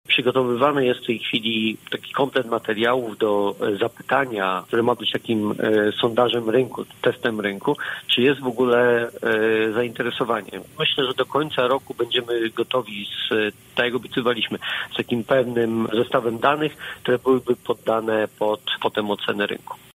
Mówi wiceprezydent Jacek Szymankiewicz: